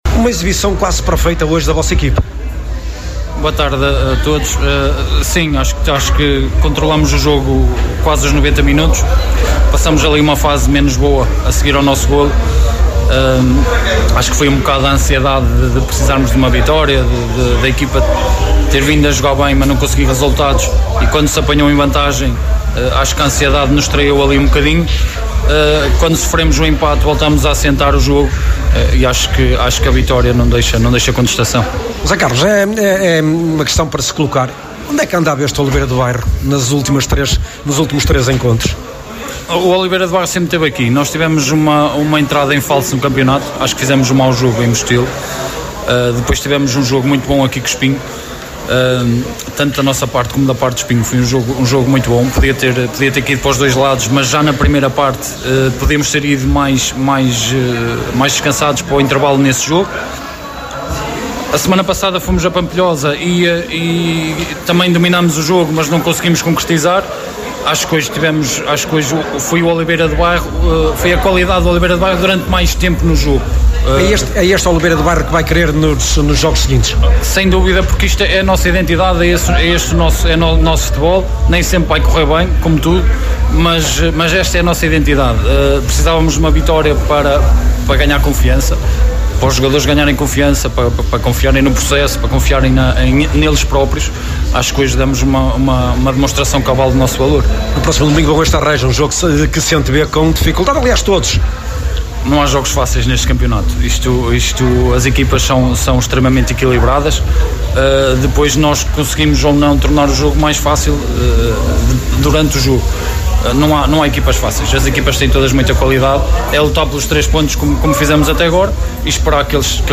No final do jogo, a Sintonia procurou ouvir os intervenientes de ambas as equipas.